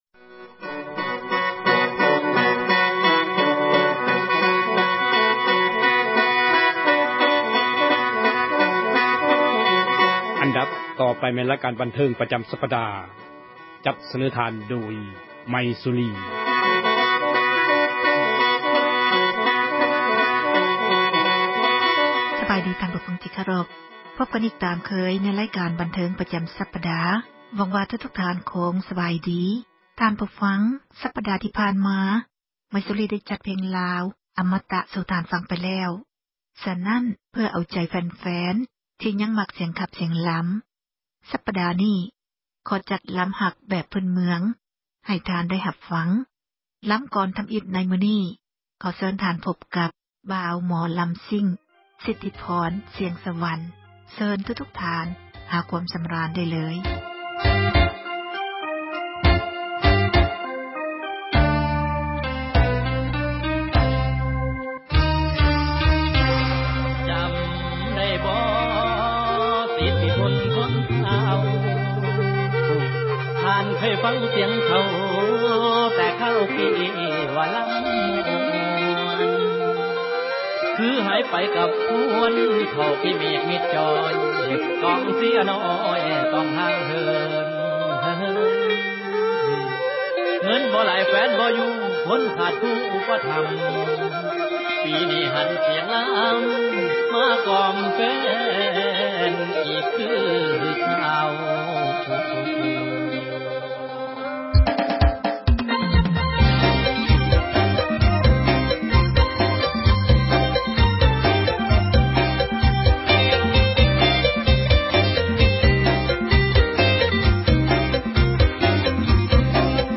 ຣາຍການ ໝໍລຳລາວ ປະຈຳ ສັປດາ ຈະນໍາເອົາ ສິລປະ ການຂັບລໍາ ທີ່ເປັນມູນ ມໍຣະດົກ ຂອງລາວ ໃນແຕ່ລະ ຊົນເຜົ່າ ແຕ່ລະ ພາກພື້ນເມືອງ ທີ່ເຮົາຄົນລາວ ຈະຕ້ອງ ສົ່ງເສີມ ແລະ ອະນຸຮັກ ຕໍ່ໄປ.
ການຫຼີ້ນ ກະຈັບປີ່ ຂອງຊົນເຜົ່າ ແຂວງ ອັຕຕະປື